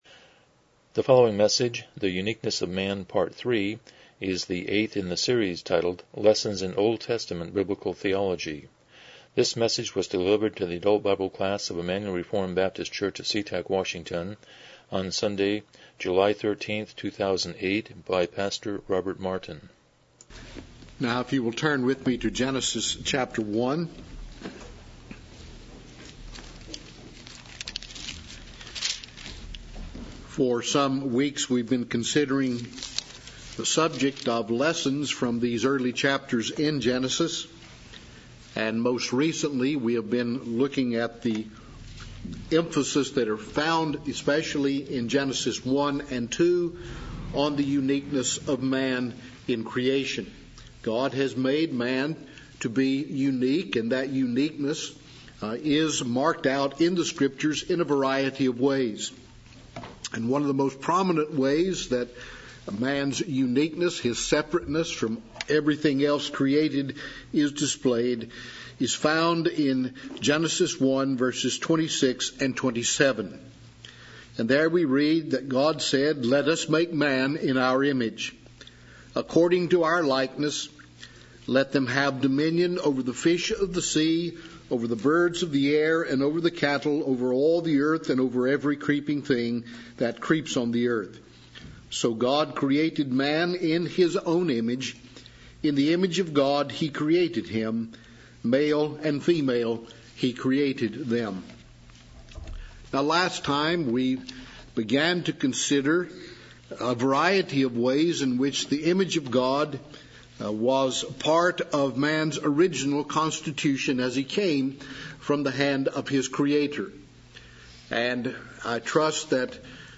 Lessons in OT Biblical Theology Service Type: Sunday School « 07 The Uniqueness of Man